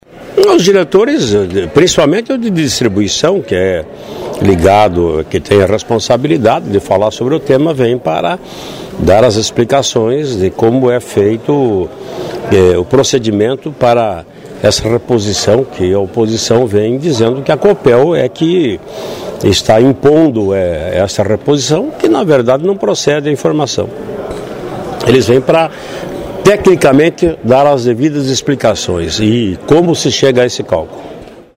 Traiano explica o motivo dos esclarecimentos.//